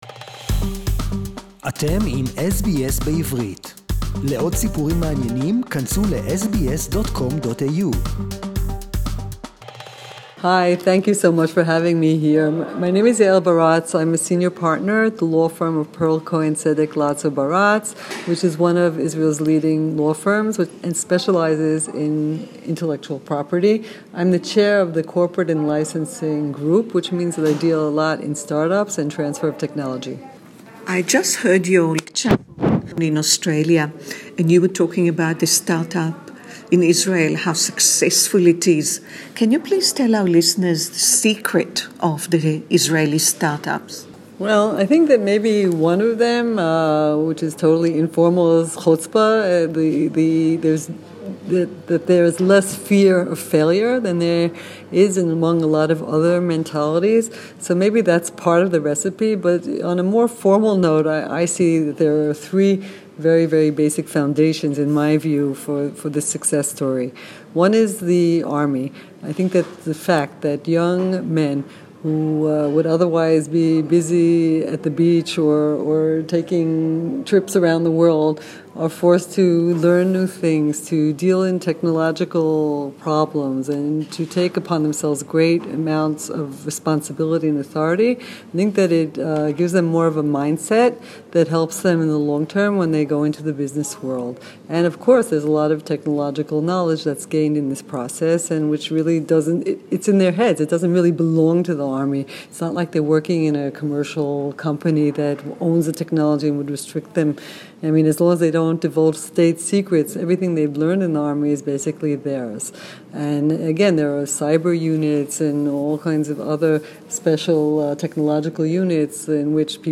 Our interview today is in English